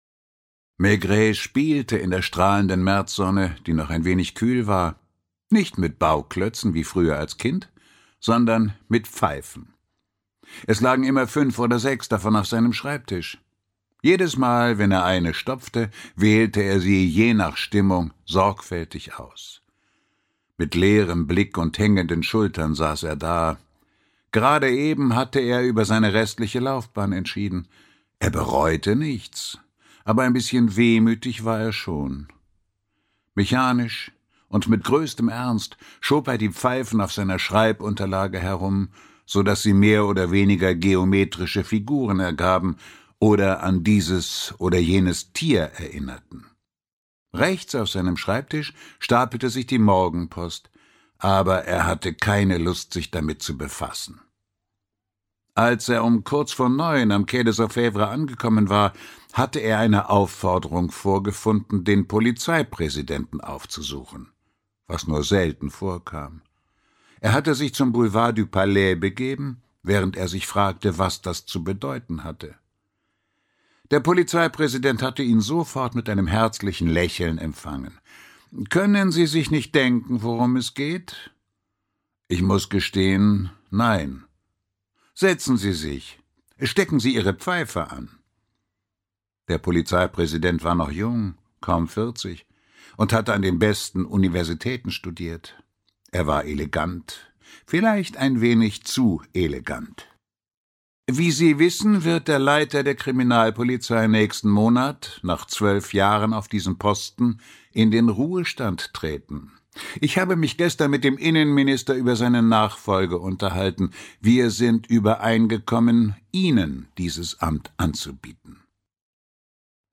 Walter Kreye (Sprecher)
Ungekürzte Lesung